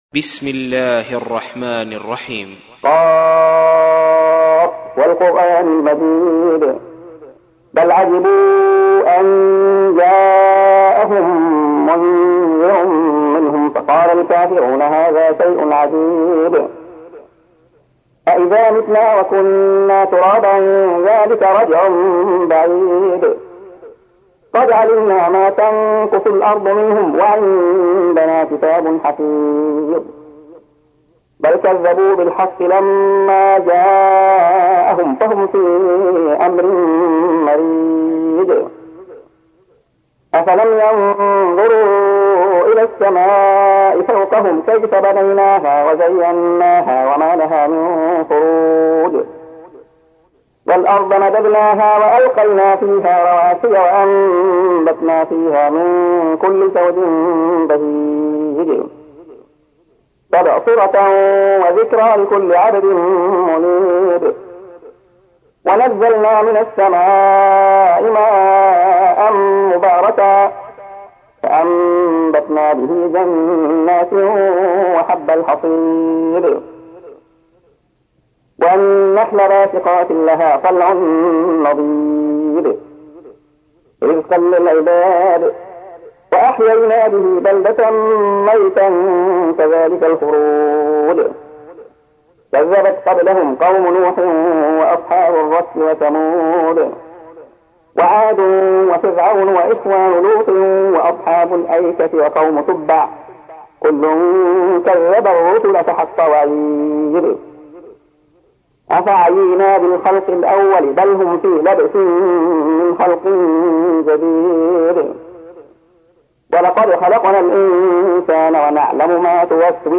تحميل : 50. سورة ق / القارئ عبد الله خياط / القرآن الكريم / موقع يا حسين